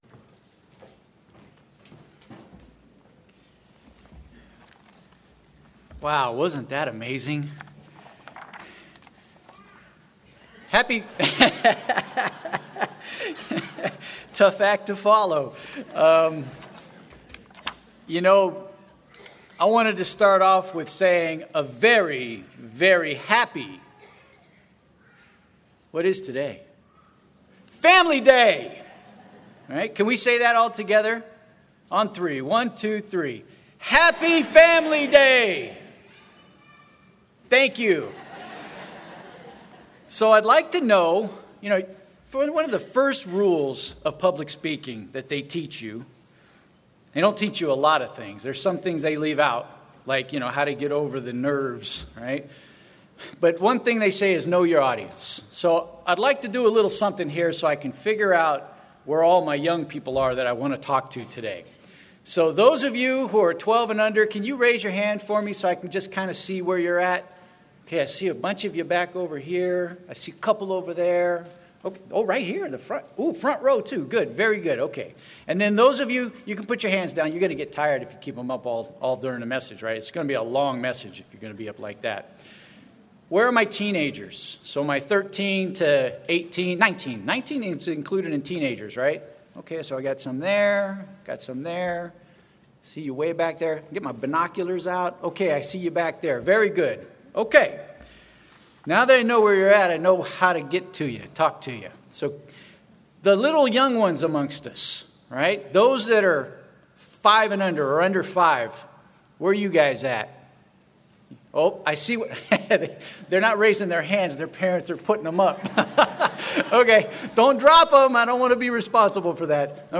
This sermon was given at the Bend-Redmond, Oregon 2022 Feast site.